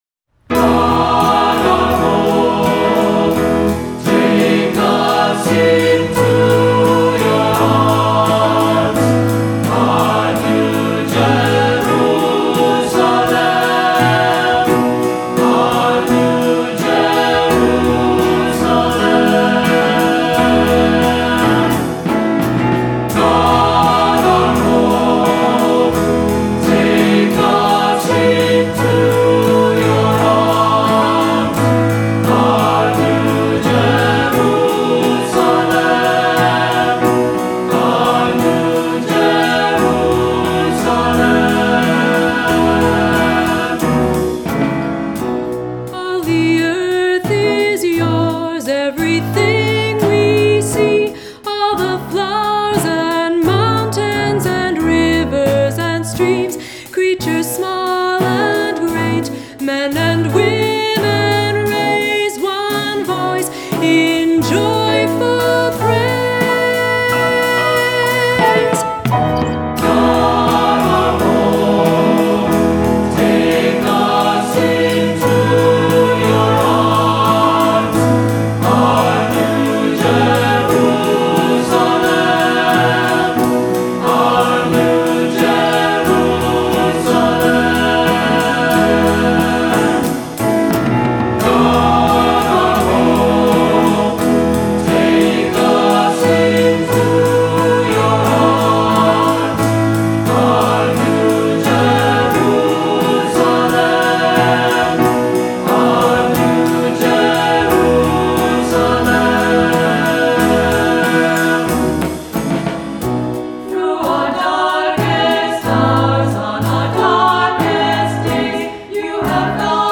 Voicing: SATB; Cantor; Assembly